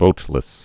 (vōtlĭs)